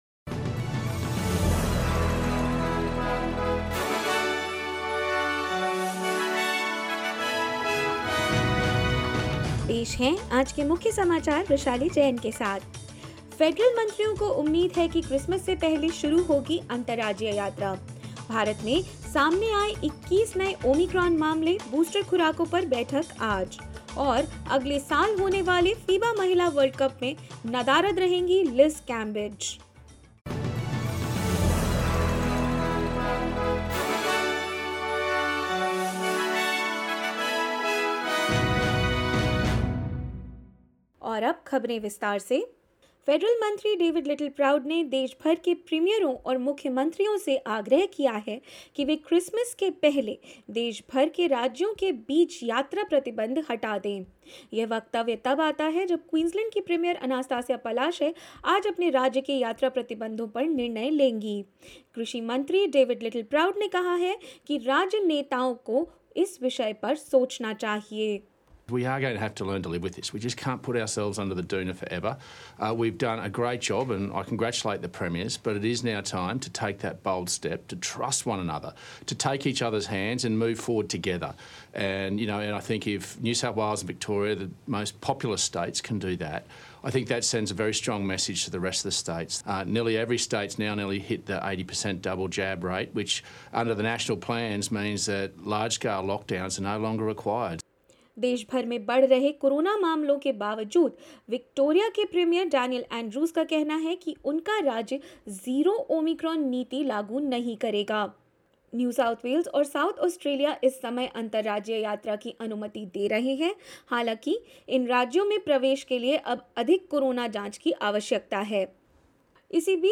In this latest SBS Hindi news bulletin of Australia and India: Federal agriculture minister David Littleproud says states should open borders by Christmas as we cannot live 'under a doona forever'; India now has 21 confirmed suspected Omicron cases and more.